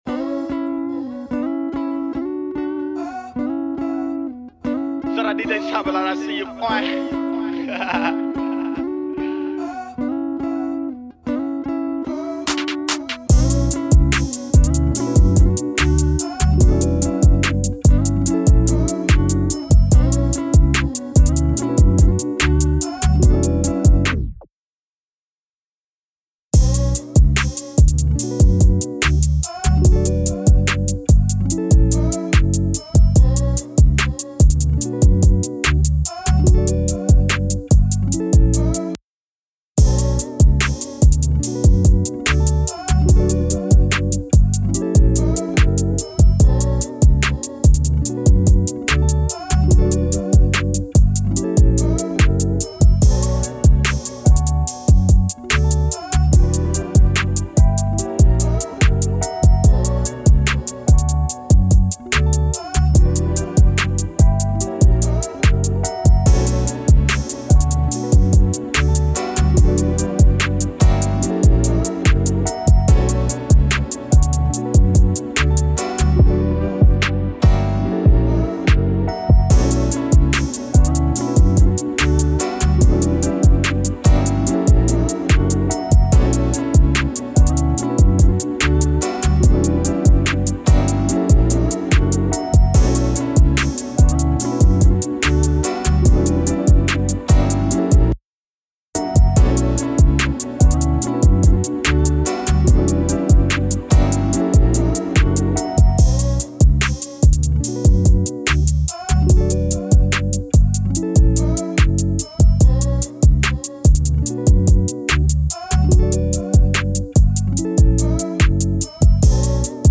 official instrumental
Hip-Hop Instrumentals